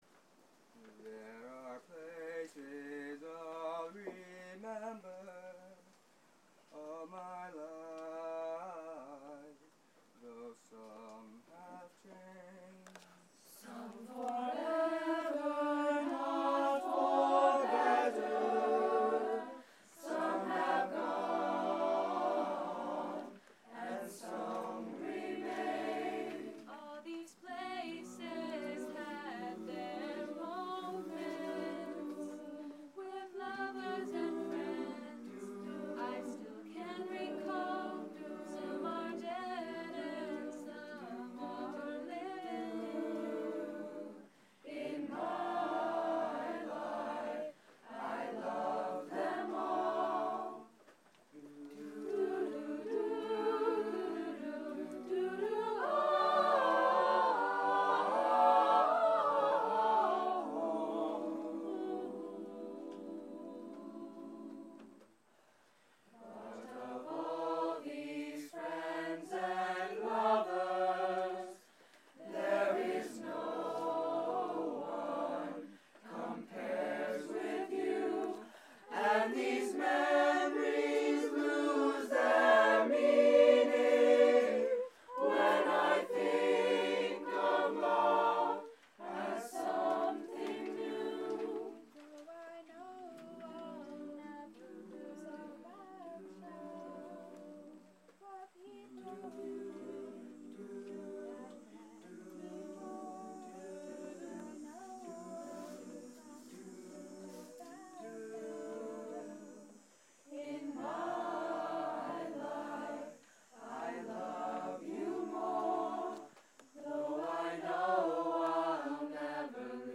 Madrigals